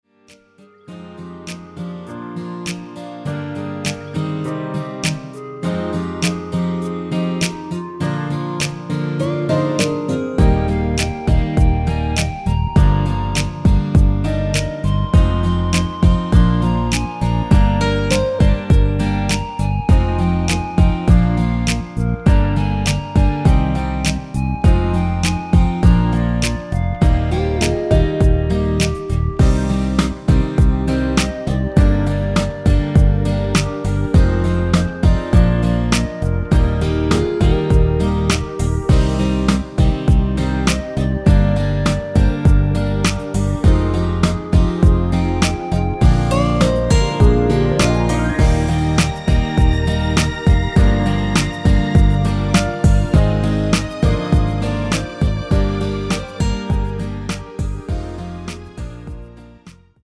멜로디 포함된 MR 입니다.(미리듣기 참조)
Eb
앞부분30초, 뒷부분30초씩 편집해서 올려 드리고 있습니다.